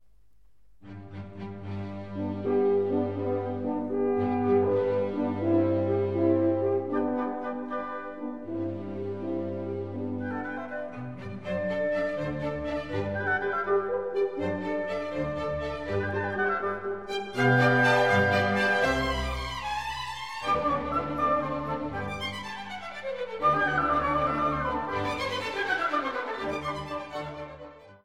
Die vorliegende Einspielung beruht auf der Uraufführung des gesamten Werks bei der Theater&Philharmonie Thüringen und wurde im September 2008 im Konzertsaal der Bühnen der Stadt Gera aufgenommen.